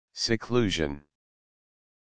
Audio Pronunciation of Seclusion